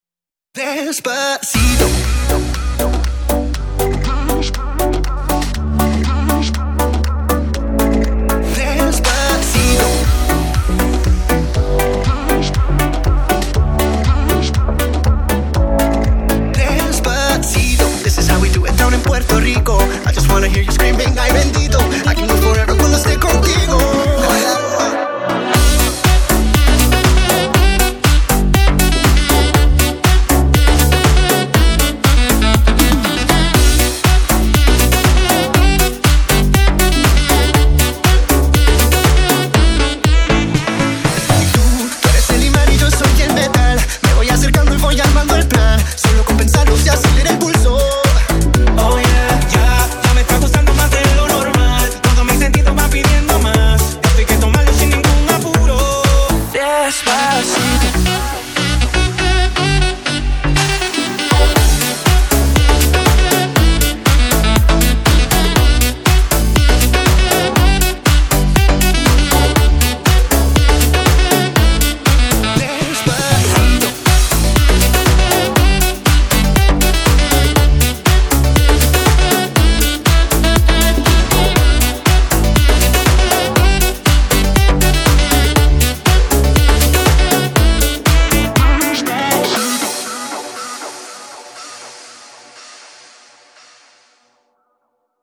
• Качество: 128, Stereo
поп
громкие
dance
Club House
Саксофон